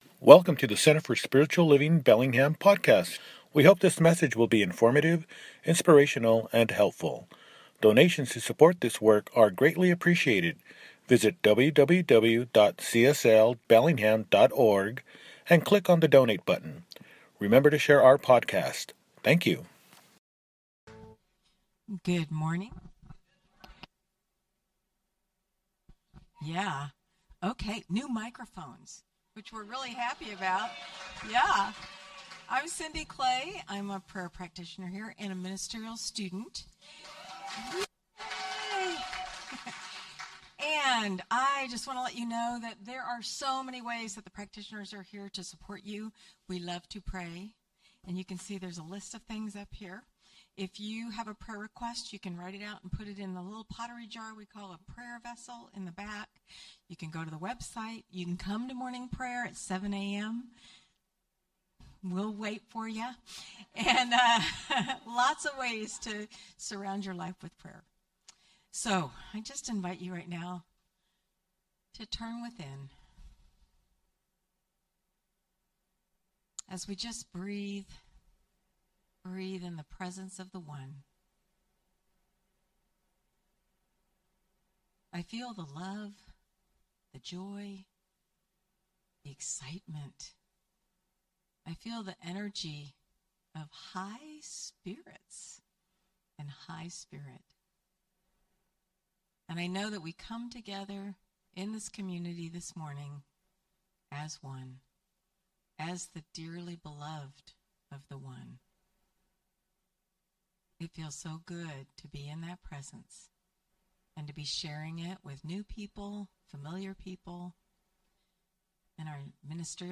Your Soul’s Grand Opening– Celebration Service – Celebration Service